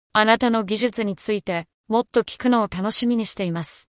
AI Voice in Japanese
Resemble Localize enables you to localize your American-English AI voice into Japanese with our advanced TTS engine.
japanese-tts.mp3